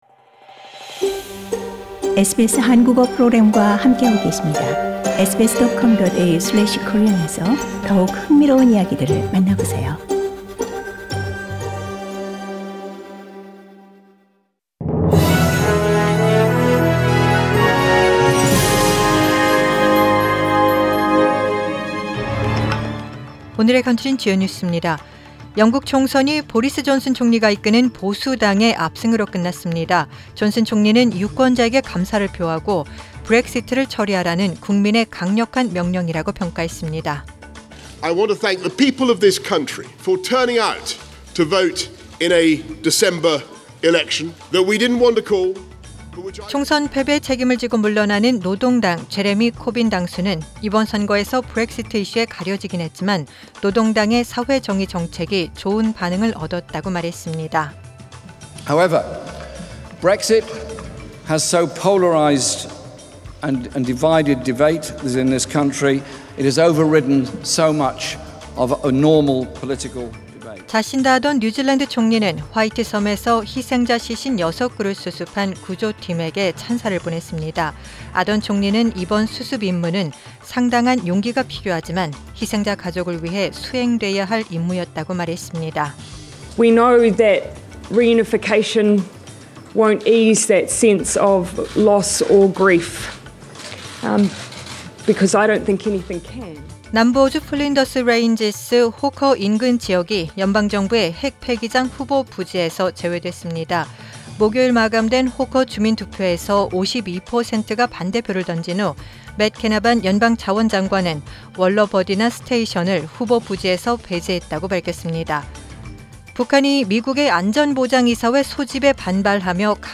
Find out Today’s top news stories on SBS Radio Korean.